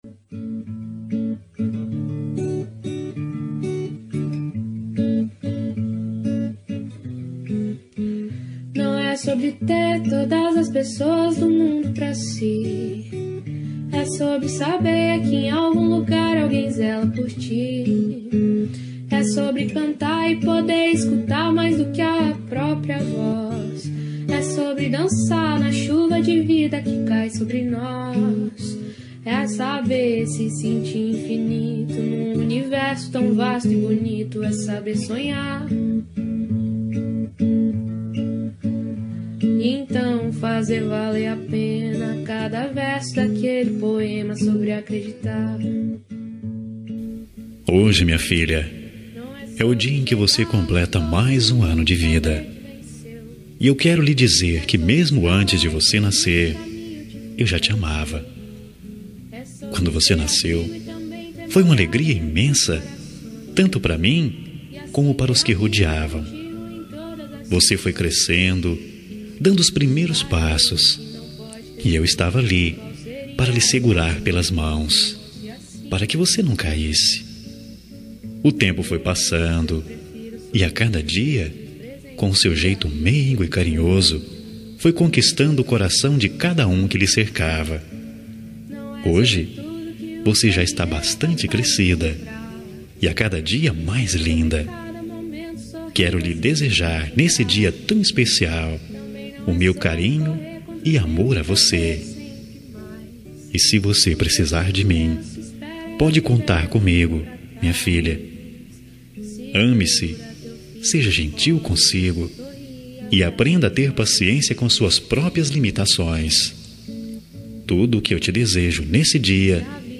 Telemensagem de Aniversário de Filha – Voz Masculina – Cód: 4213 – Linda